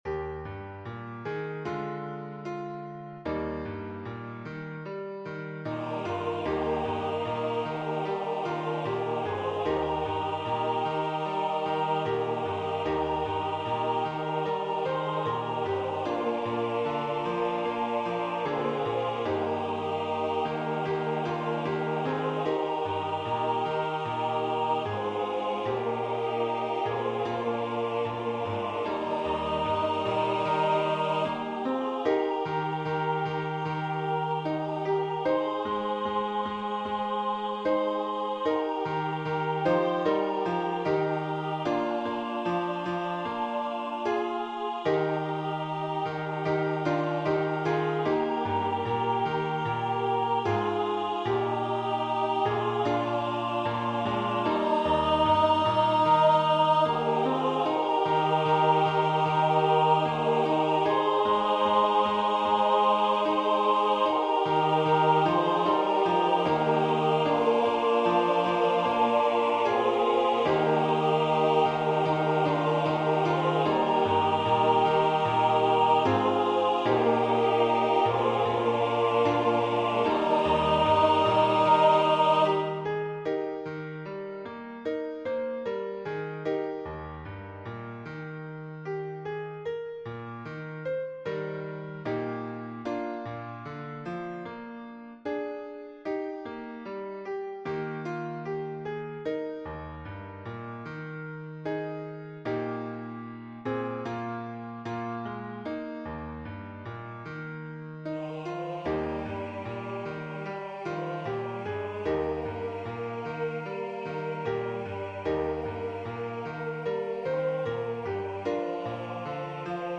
A Cappella/Optional A Capella